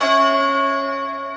Key-bell_90.1.1.wav